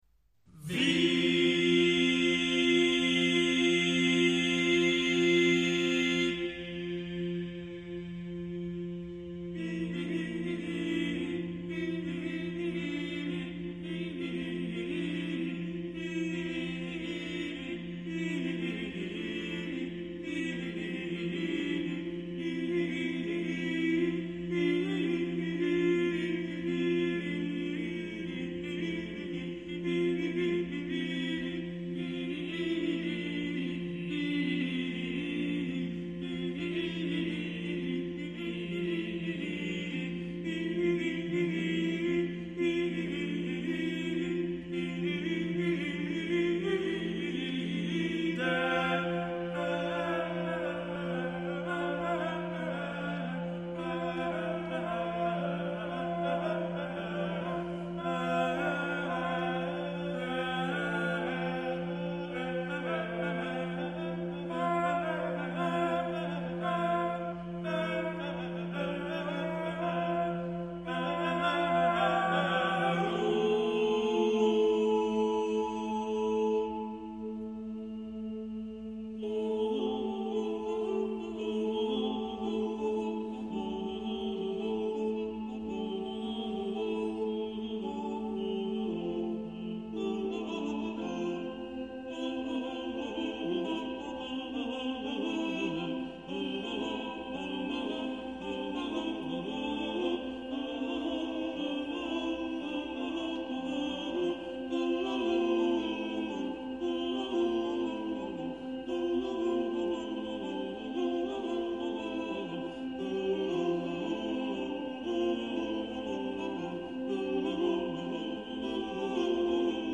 a due voci